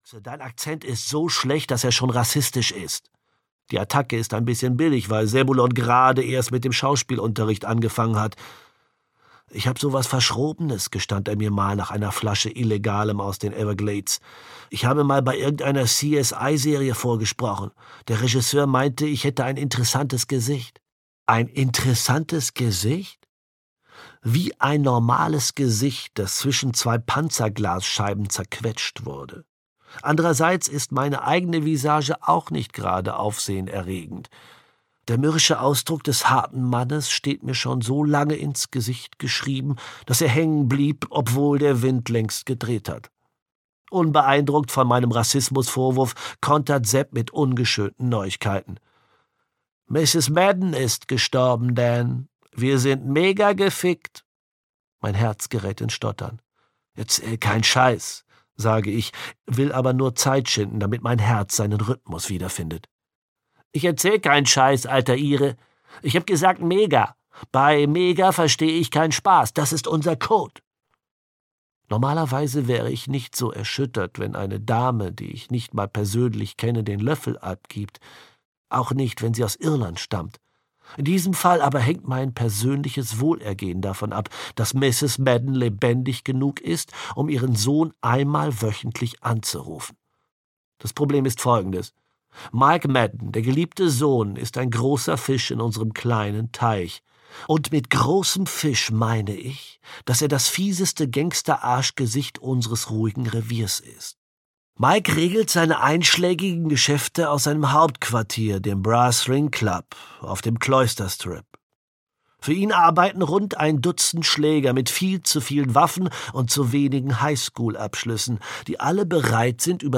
Hinterher ist man immer tot - Eoin Colfer - Hörbuch